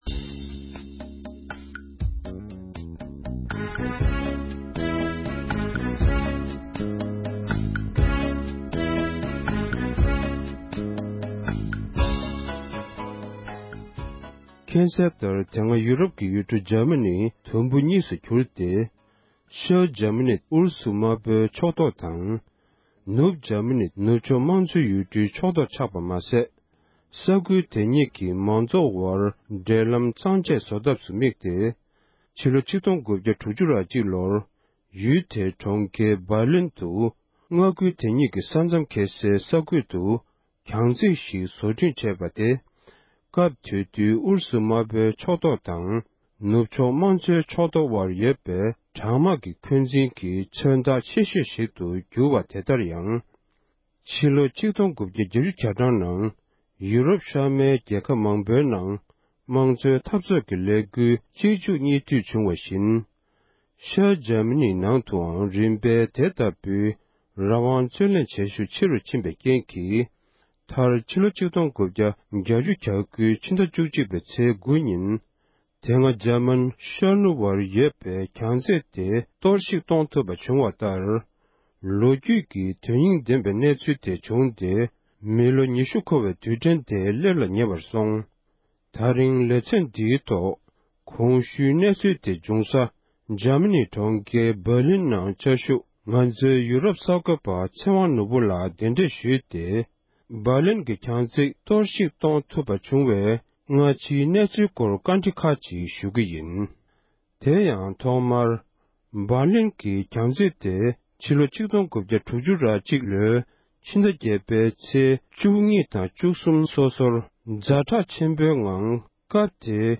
གནས་འདྲི་ཞུས་པའི་དུམ་མཚམས་དང་པོར་གསན་རོགས